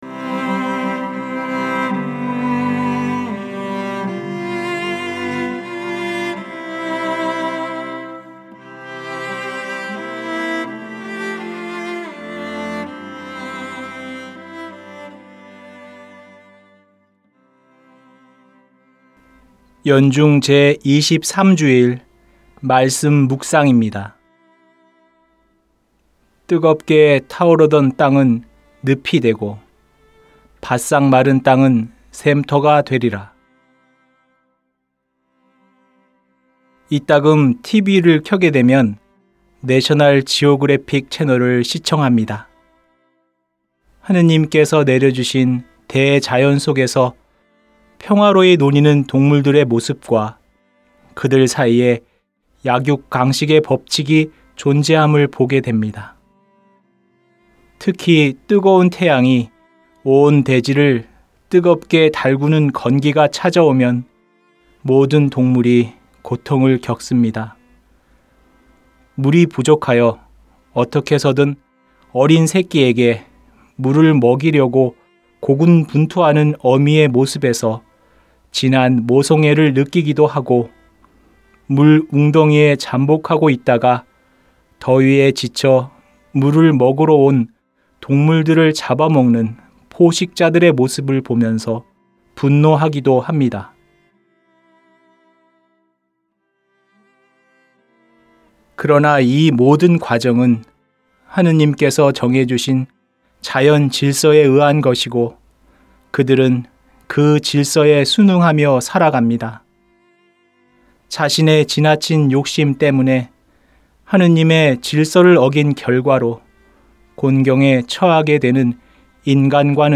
2021년 9월 5일 연중 제23주일 - 말씀묵상 듣기(☜파란색 글씨를 클릭하세요)